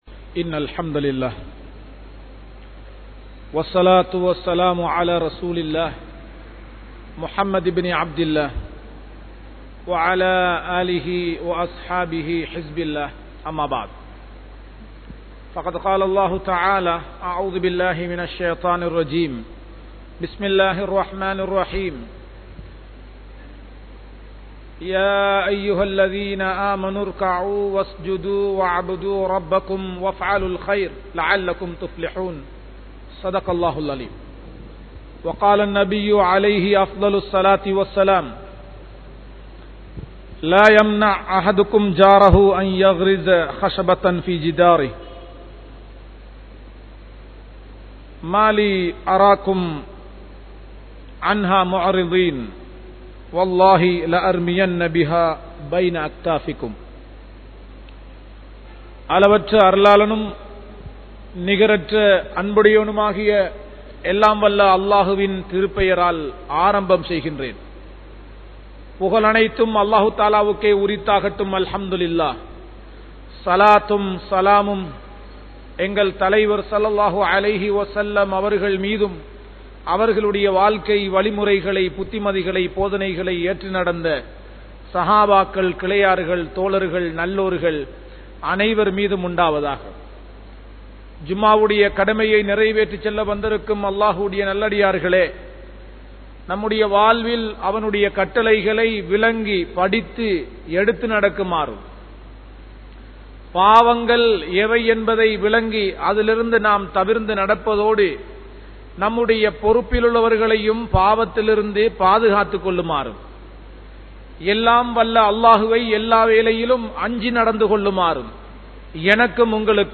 Suyanalavaathihalaaha Vaalatheerhal (சுயநலவாதிகளாக வாழாதீர்கள்) | Audio Bayans | All Ceylon Muslim Youth Community | Addalaichenai